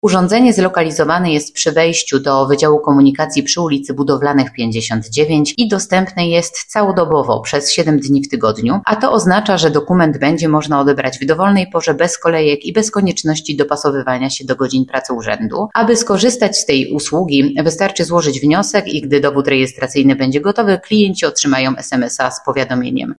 – mówi Maciej Gramatyka, prezydent Miasta Tychy.